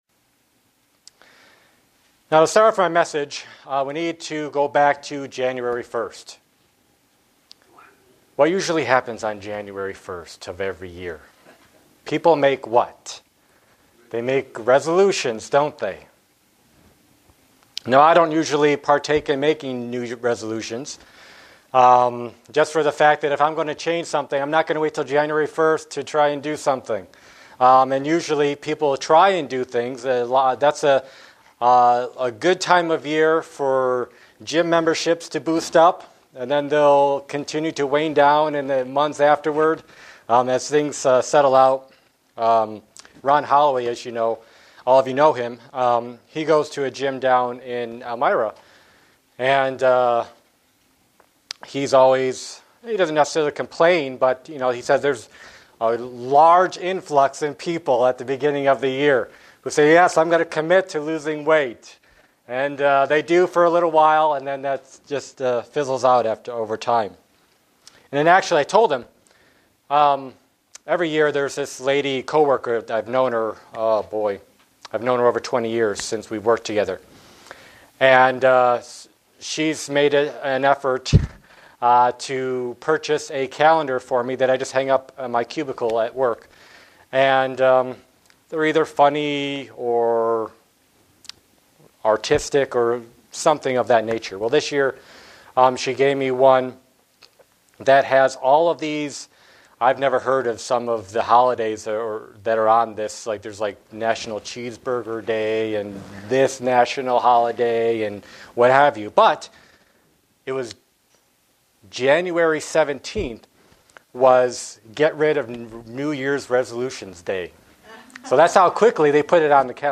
Print Repentance is a central part of the message of Jesus. sermon Studying the bible?